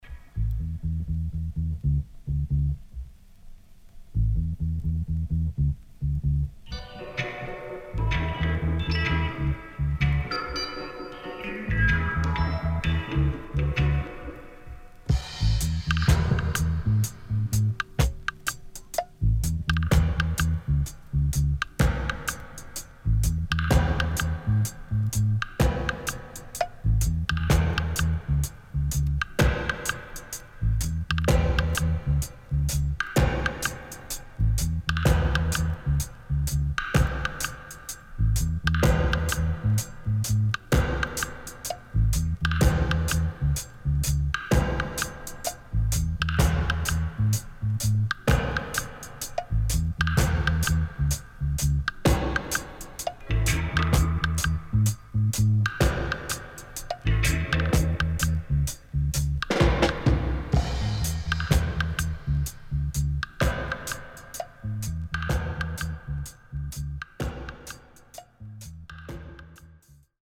Sweet & Slow
SIDE A:少しチリノイズ入ります。